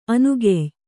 ♪ anugey